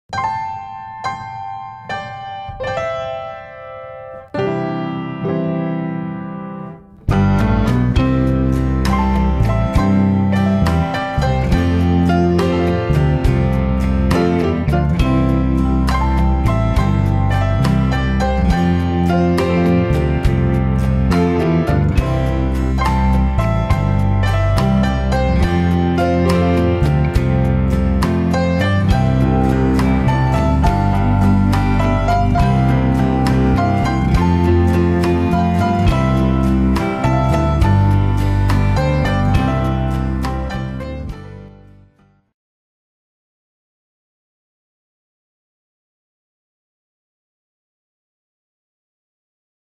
20 CLASSIC PIANO INSTRUMENTALS